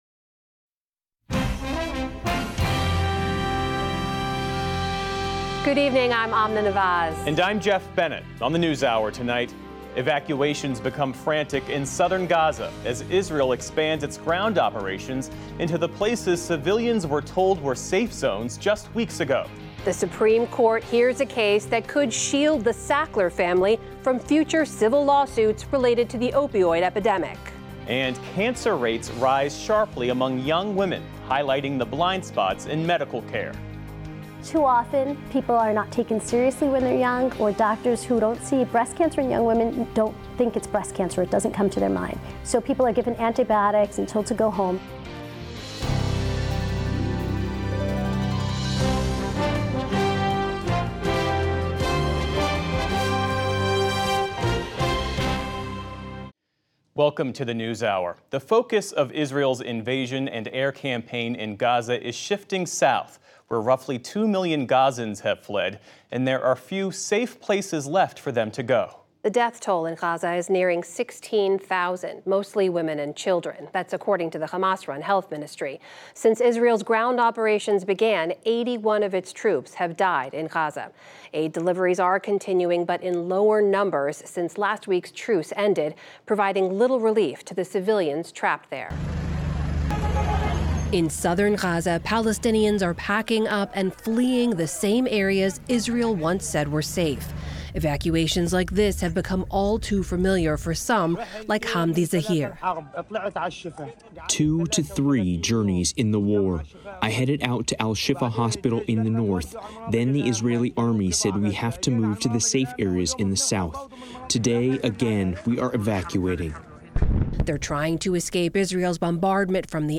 Listen to the complete PBS News Hour, specially formatted as a podcast. Published each night by 9 p.m., our full show includes every news segment, every interview, and every bit of analysis as our television broadcast.